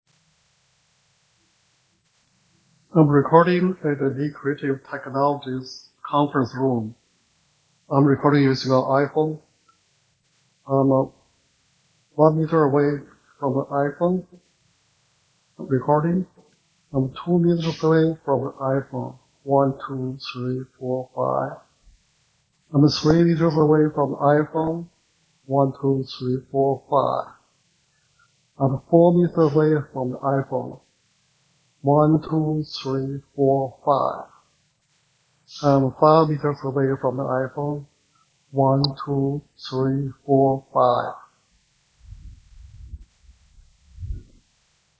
Demo 1 – Large Conference Room
After LCT dereverberation and denoise:
Audio2_LCT_Dereverberation_LargeRoom__on_iPhone_Recording.wav